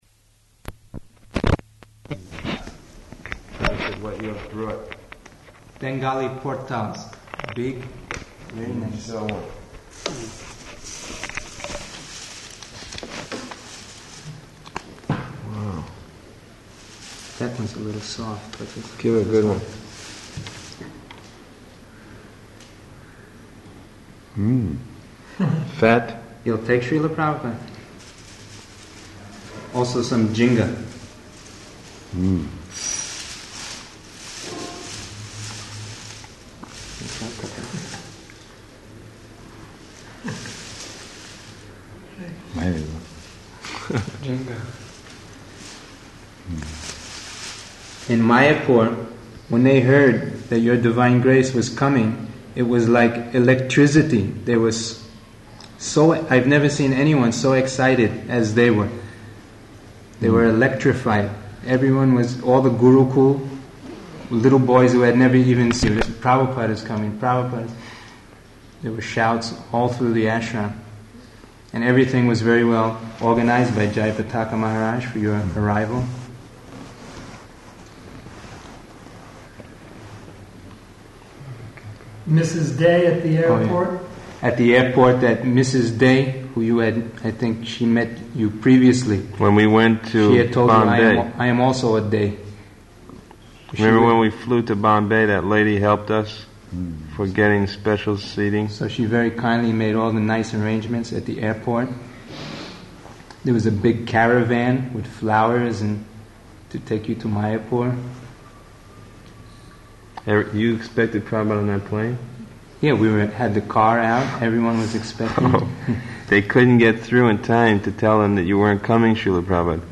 -- Type: Conversation Dated: November 3rd 1977 Location: Vṛndāvana Audio file